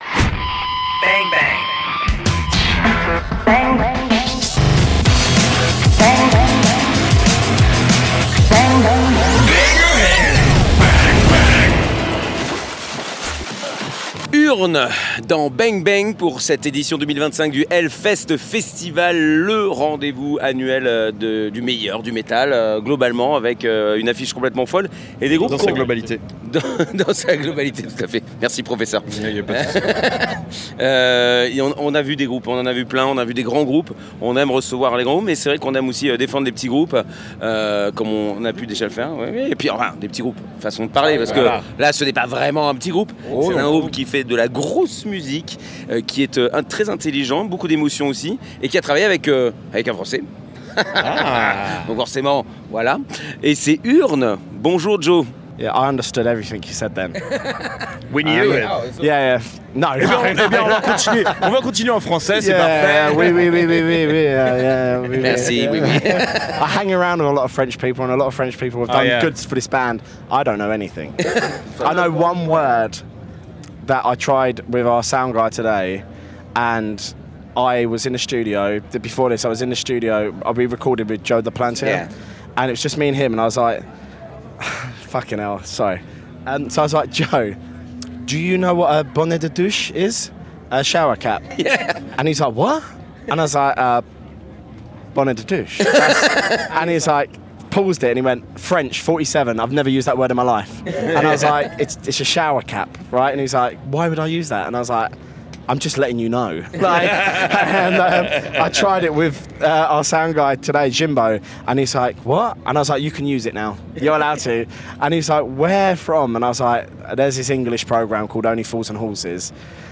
Et nous avons fait 23 interviews !